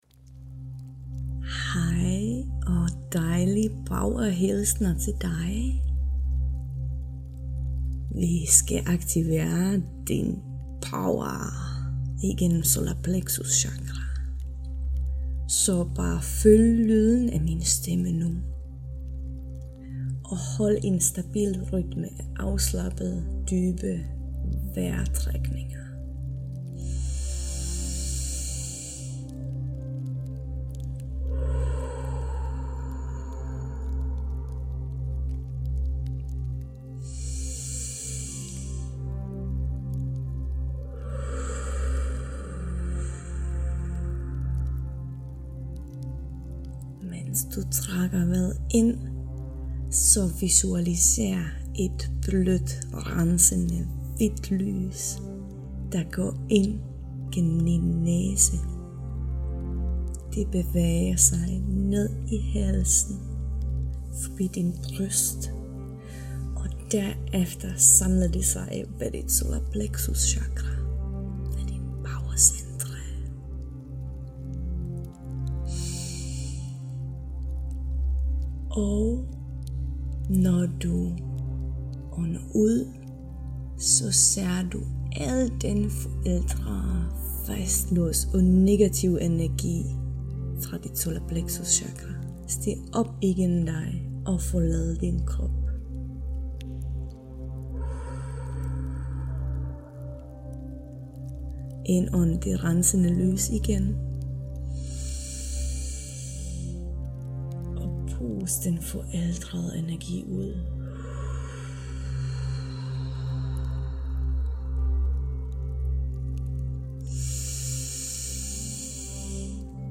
SOLAR PLEXUS CHAKRA meditation
Den guidede meditation tager ca. tre minutter, hvorefter musikken fortsætter op til otte minutter, så du kan nyde øjeblikket, hvis du har behov for det.